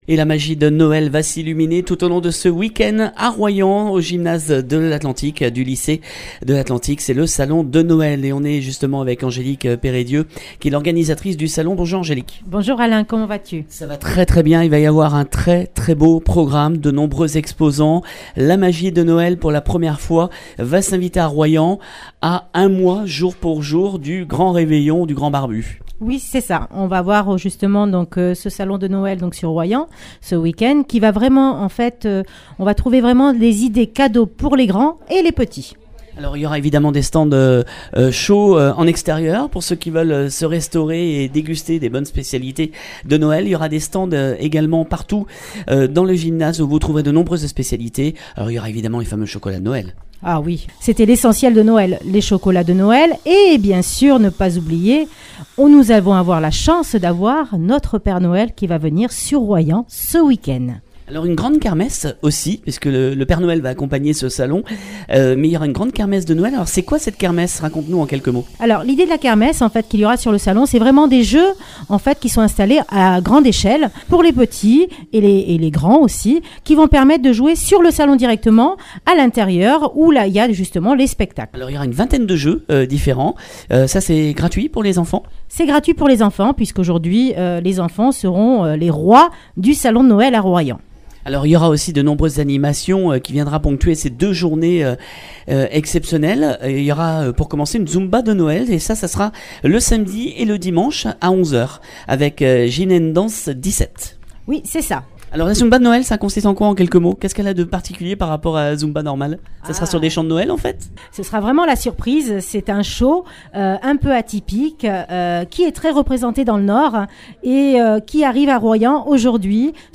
voici le programme détaillé à mon micro